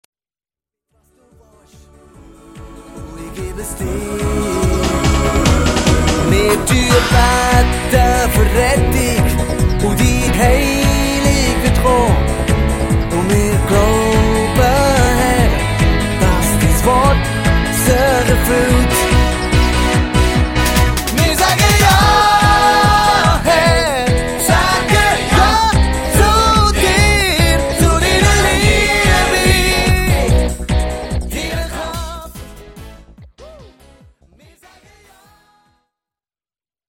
Songs (Lead Vocals)